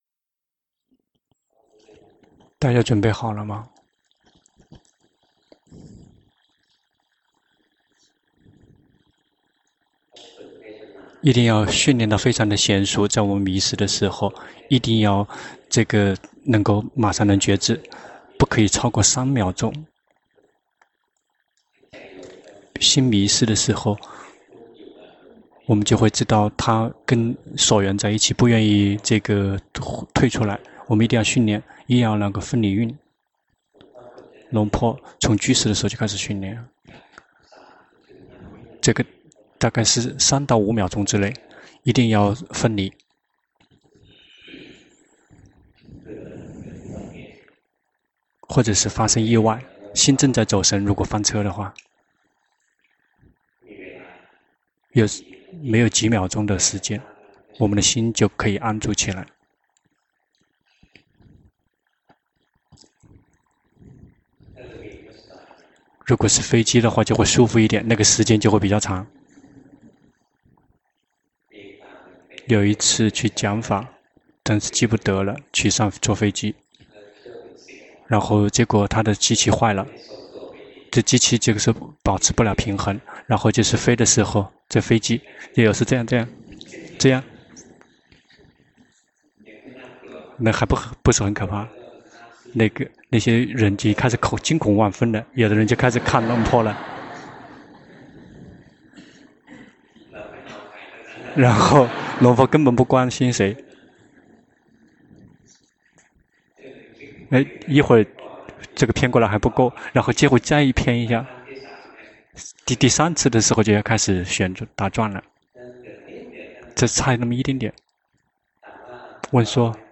長篇法談｜殊勝的自我省察